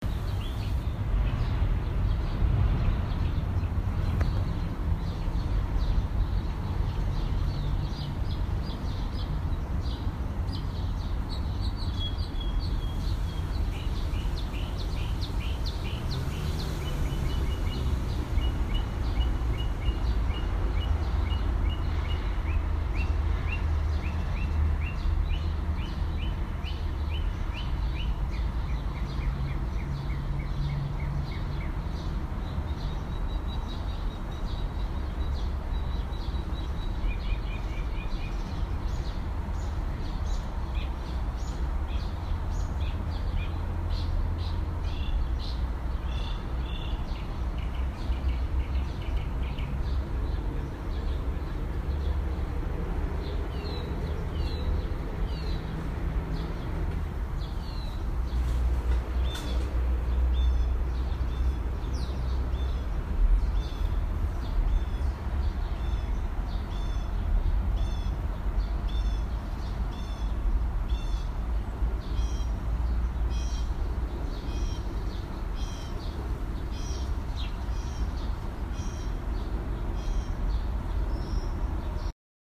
描述：在布鲁克林录制哥伦比亚街。公共汽车，鸟类，汽车。
Tag: 总线 交通 气氛 现场录音 城市